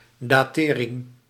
Ääntäminen
IPA: [dat]